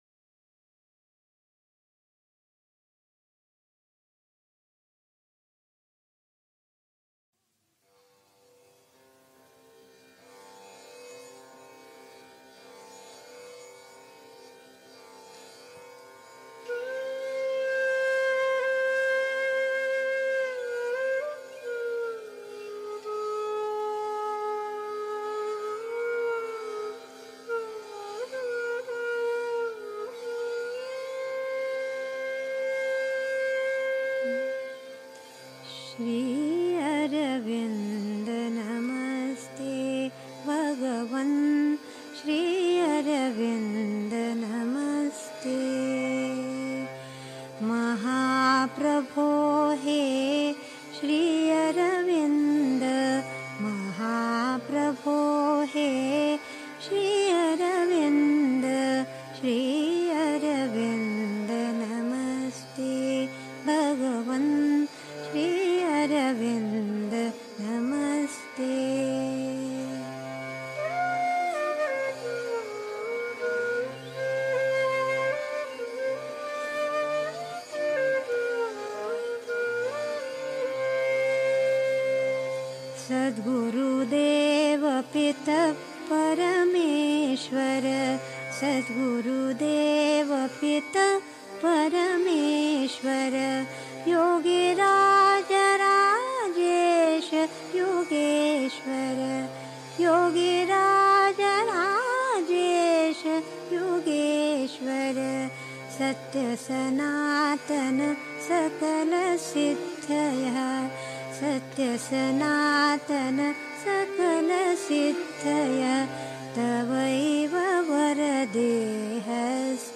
1. Einstimmung mit Musik. 2. Die Regel für den Yoga (Sri Aurobindo, SABCL, Vol. 23, pp. 604-05) 3. Zwölf Minuten Stille.